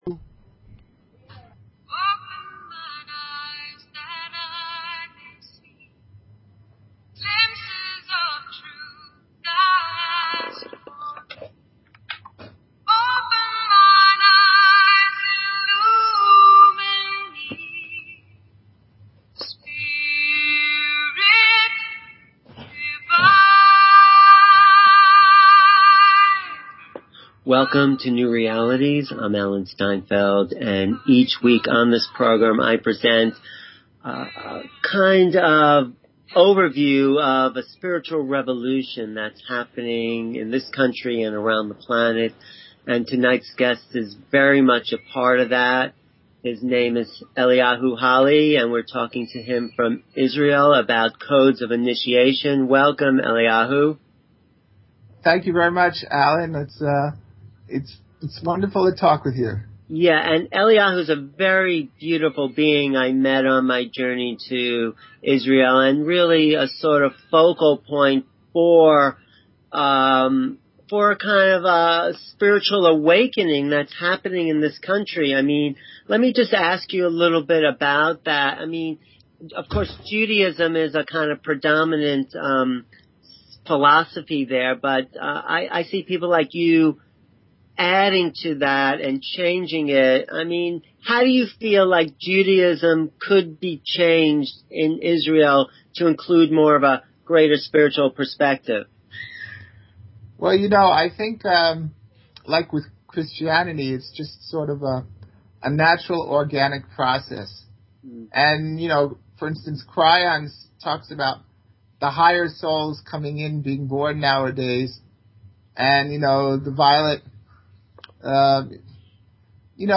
Talk Show Episode, Audio Podcast, New_Realities and Courtesy of BBS Radio on , show guests , about , categorized as